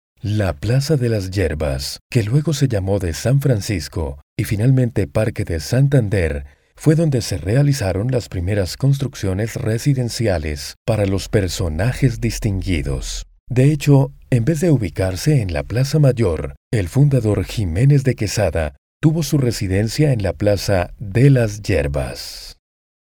Colombian voice over. Colombian voice over agency
Colombian male voices